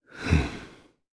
Clause_ice-Vox_Sigh_jp.wav